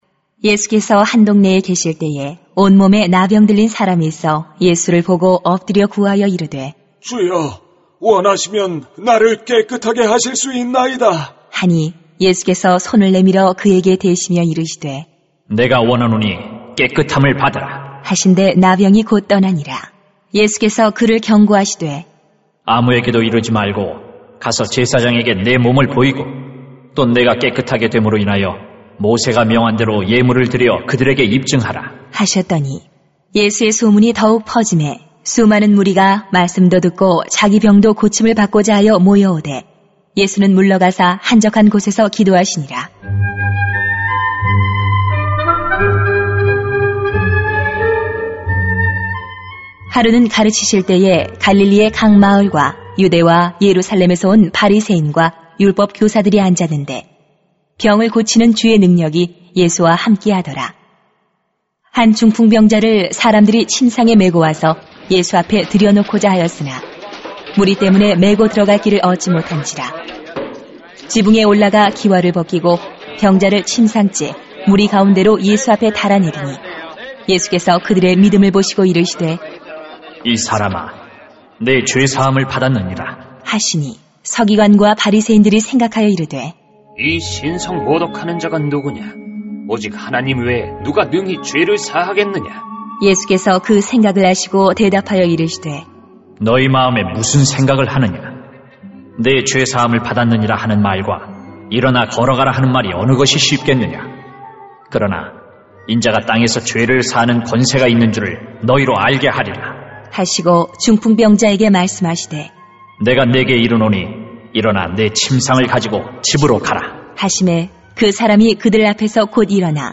[눅 5:12-26] 예수님이 우리를 온전하게 하십니다 > 새벽기도회 | 전주제자교회